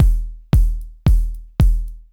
DWS KIK HH-L.wav